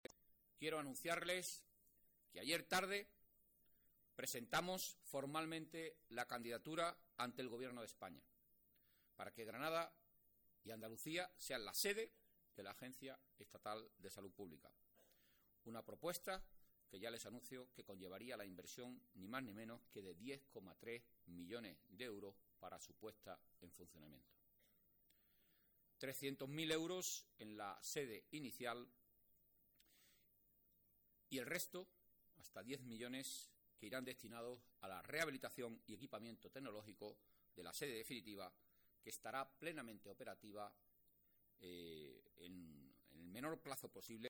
Declaraciones de Antonio Sanz